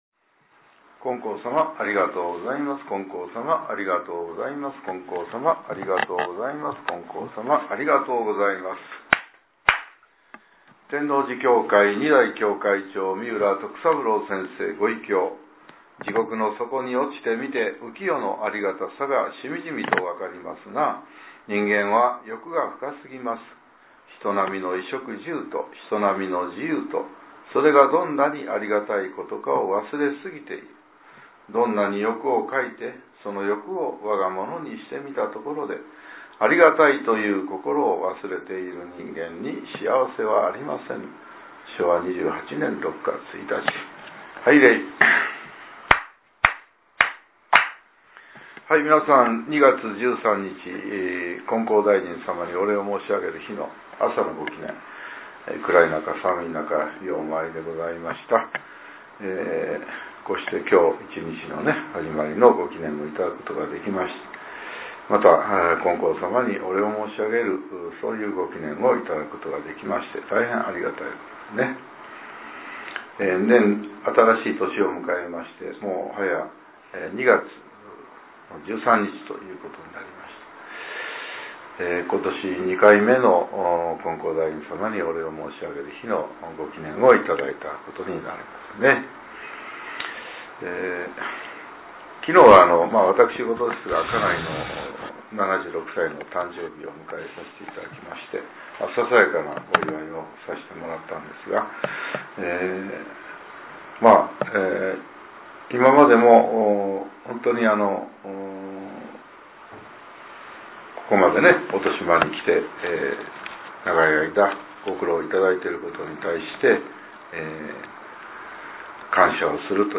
令和８年２月１３日（朝）のお話が、音声ブログとして更新させれています。